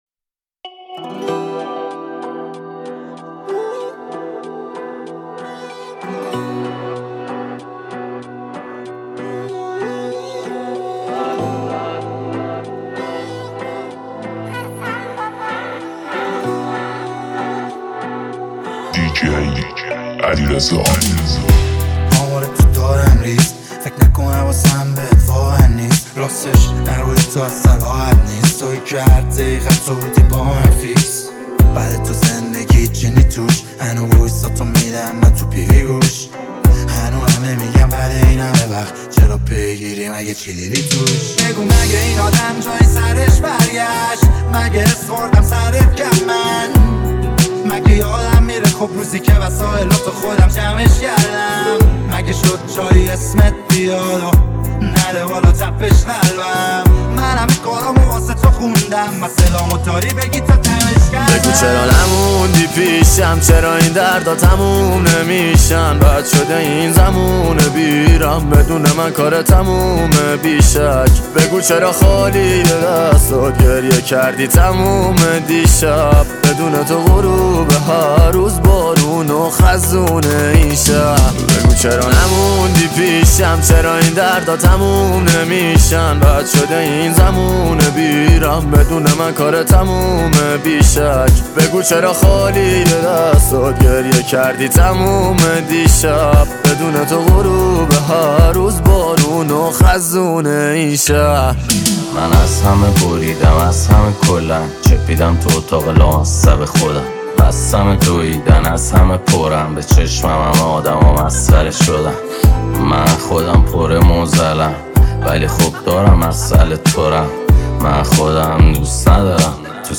ریمیکس رپ
اهنگ غمگین